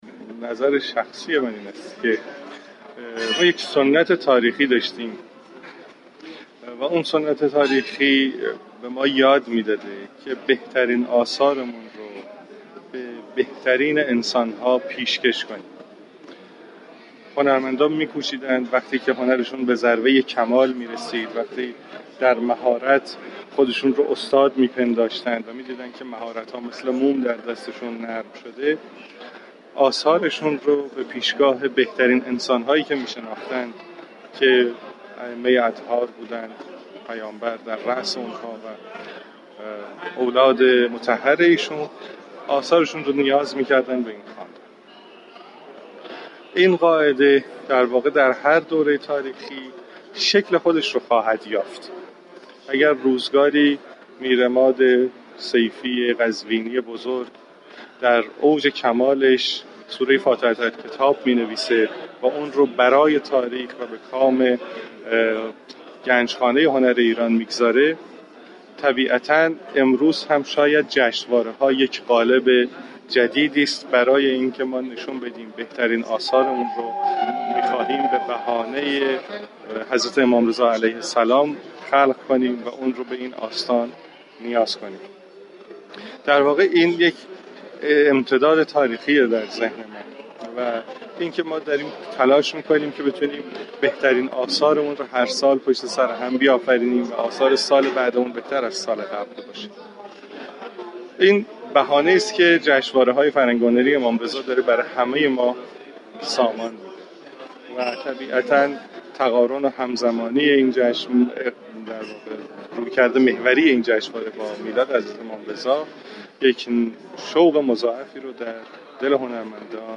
دكتر سید مجتبی حسینی معاون امور هنری وزارت فرهنگ و ارشاد اسلامی در گفتگوی اختصاصی با خبرنگار سایت رادیو فرهنگ ضمن تبریك میلاد حضرت امام رضا علیه السلام درباره دستاورد های جشنواره بین المللی فرهنگی، هنری امام رضا علیه السلام گفت ما سنت های تاریخی بسیاری داشته ایم و سنت های تاریخی به ما آموختند تا بهترین آثار را به بهترین اشخاص هدیه كنیم و هنرمندان می‌كوشیدند تا زمانی كه هنرشان به مرحله كمال رسید آثار خود را به پیشگاه بهترین انسان‌ها هدیه كنند این قاعده در هر دوره تاریخی شكل خود را خواهد یافت اگر روزگاری میرعماد سیفی بصیری بزرگ در اوج كمال كتابی می‌نگارد و آن كتاب ارزشمند را برای حفظ تاریخ به گن